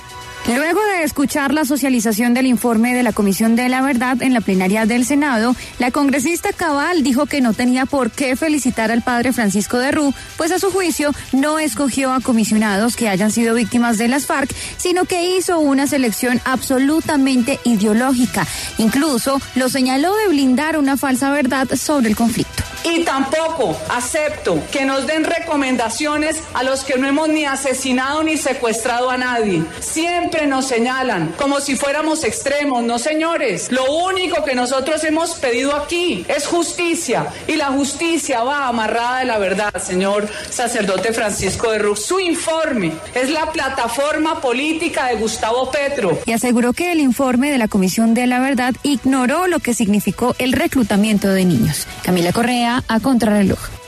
La congresista del Centro Democrático arremetió contra el presidente de la Comisión de la Verdad en la plenaria del Senado.